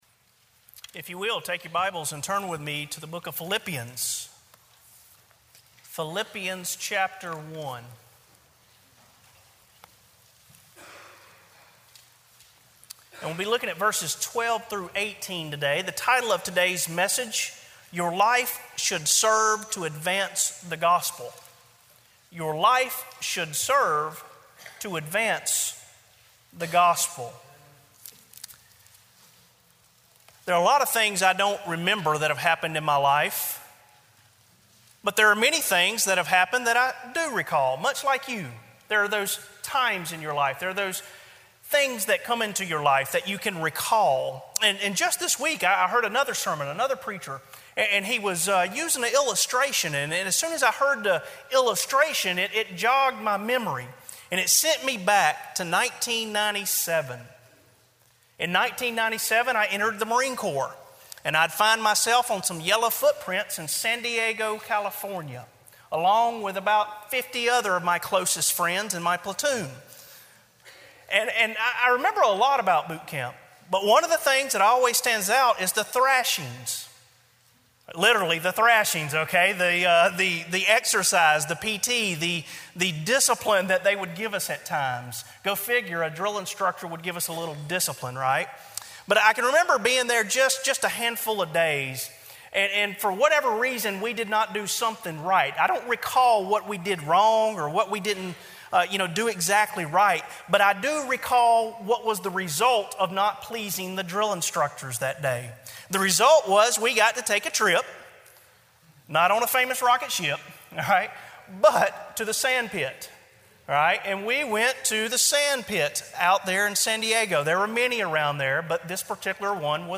Sermon Audios/Videos - Tar Landing Baptist Church
Morning WorshipPhilippians 1:12-18